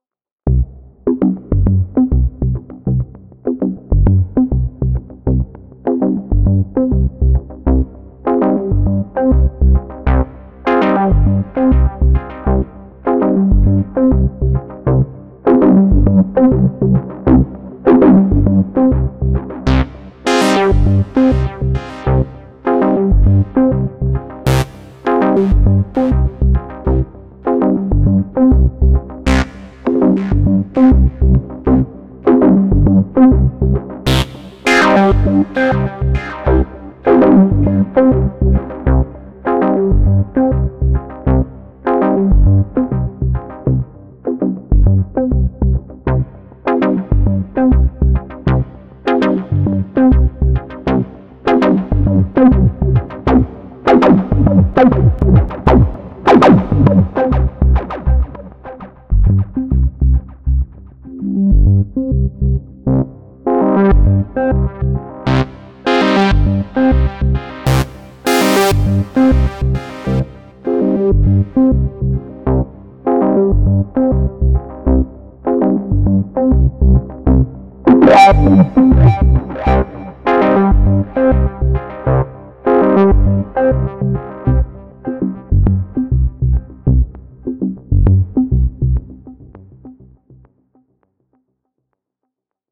• Improve the sound quality by using a state-of-the-art lowpass filter
• Make a synth that behaves much more like an old analog synthesizer
Demos contributed by different people: